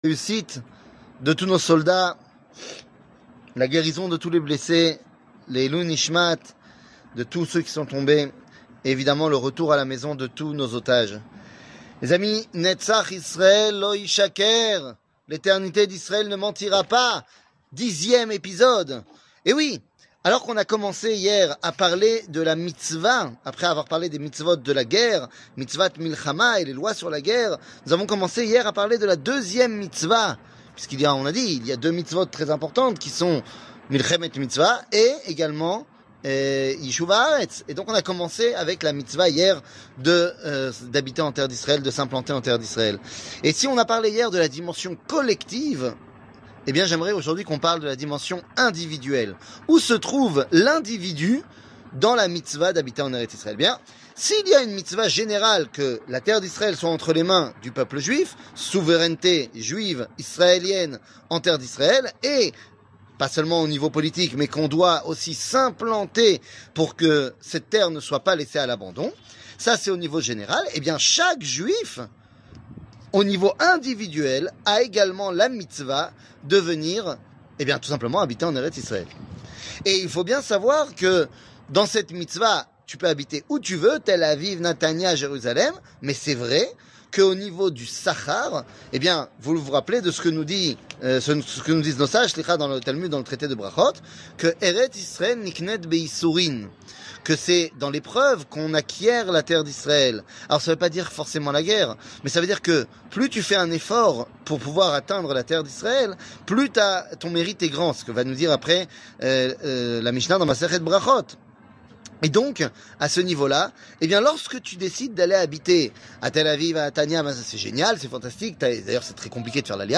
L eternite d Israel ne mentira pas ! 10 00:09:02 L eternite d Israel ne mentira pas ! 10 שיעור מ 19 אוקטובר 2023 09MIN הורדה בקובץ אודיו MP3 (8.27 Mo) הורדה בקובץ וידאו MP4 (12.95 Mo) TAGS : שיעורים קצרים